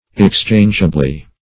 exchangeably - definition of exchangeably - synonyms, pronunciation, spelling from Free Dictionary Search Result for " exchangeably" : The Collaborative International Dictionary of English v.0.48: Exchangeably \Ex*change"a*bly\, adv. By way of exchange.